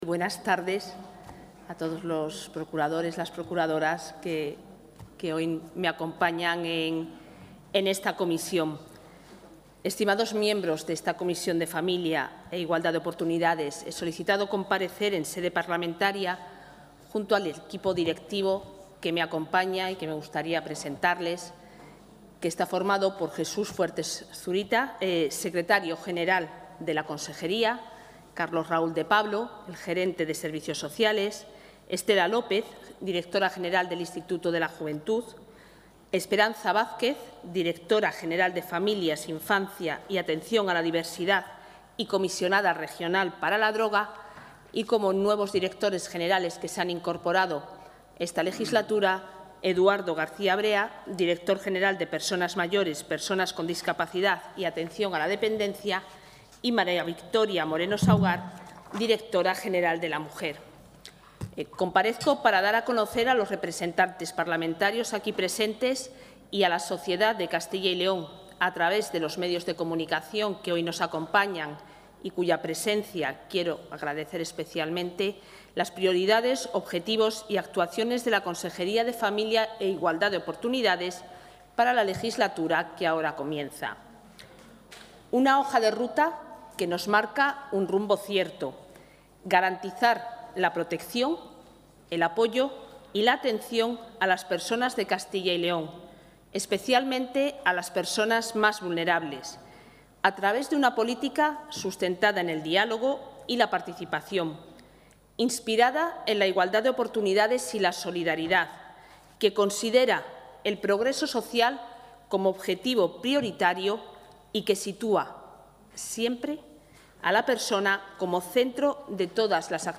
La titular de Familia e Igualdad de Oportunidades, Isabel Blanco, ha comparecido esta tarde en la sede de las Cortes de Castilla y León para...